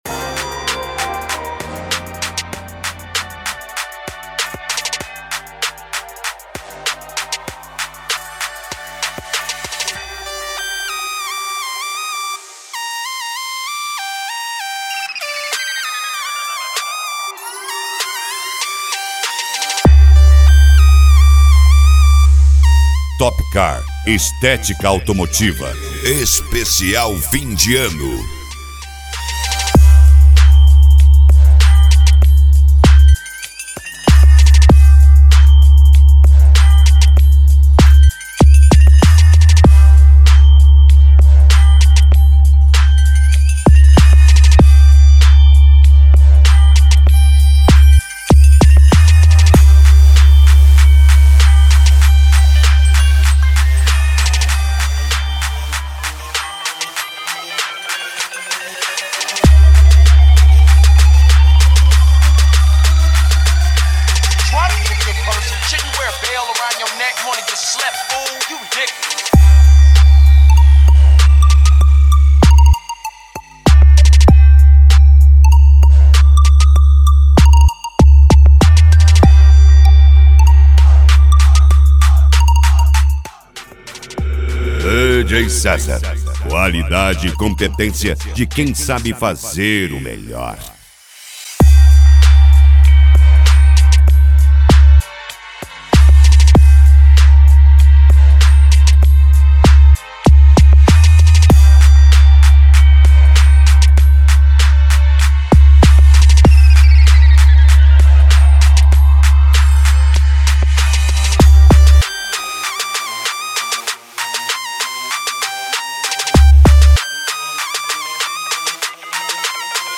Funk
Mega Funk
Melody
Modao